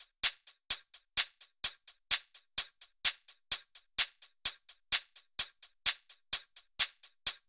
VDE 128BPM Silver Drums 4.wav